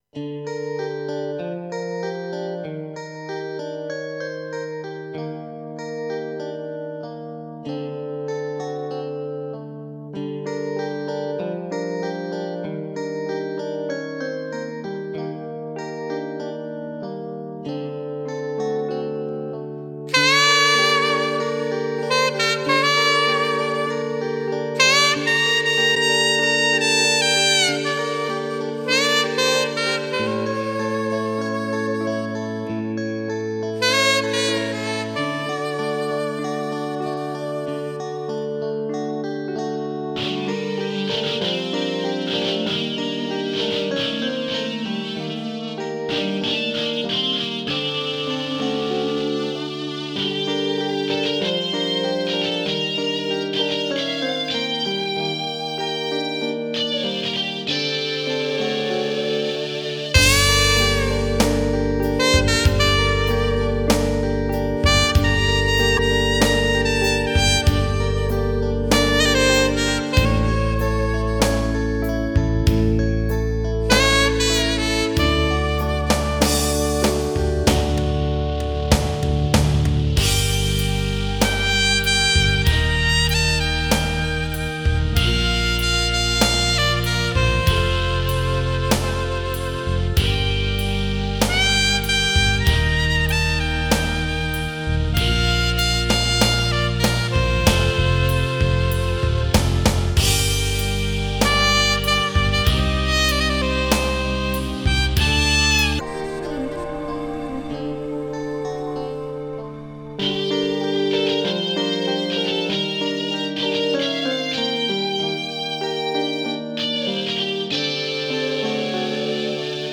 Здесь вообще запись частичная...а нужна качественная.